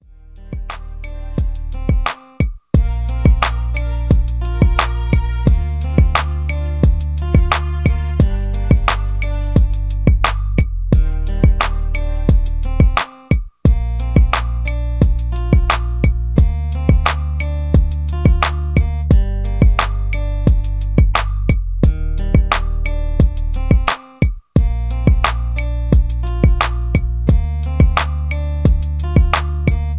Mid Tempo R&B Beat